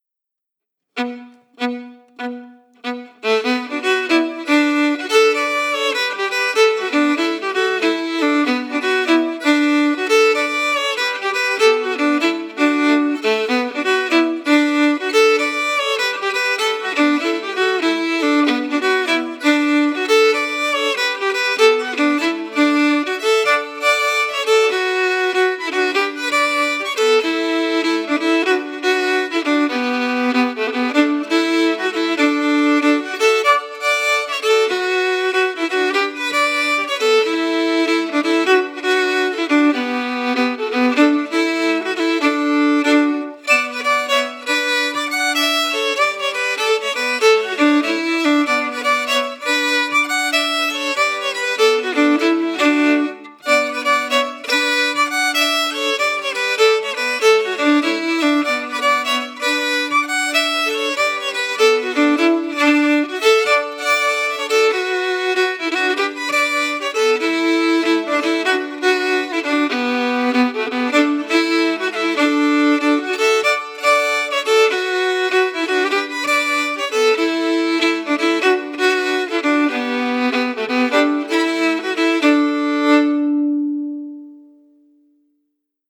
Key: Bm
Form: Jig
Melody emphasis
Region: Shetland